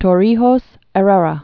(tôr-rēhōs ĕr-rĕrä), Omar 1929-1981.